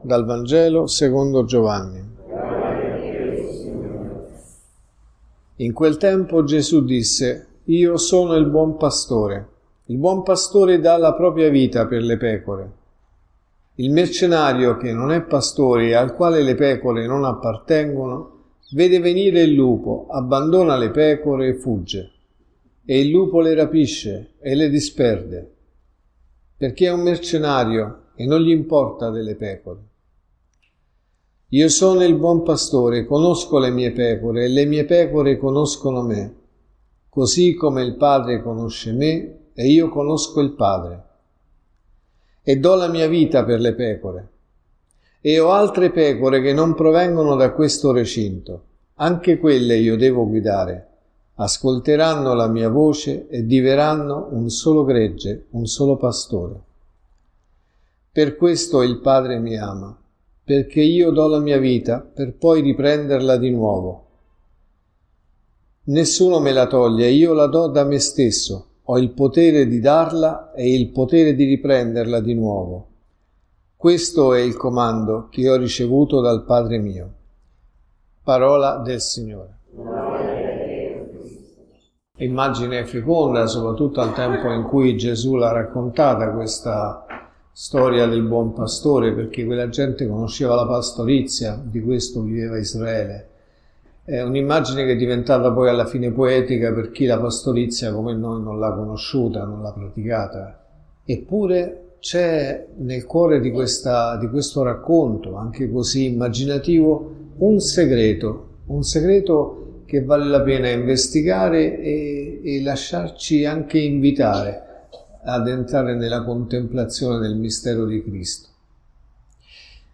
Il buon pastore dà la propria vita per le pecore.(Messa del mattino e della sera)
Omelie